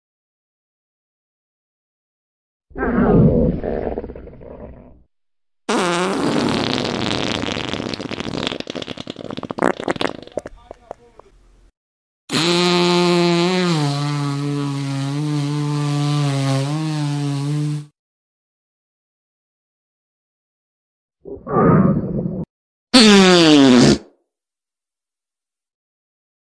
THAT EMOJI CAT POOP SOUND sound effects free download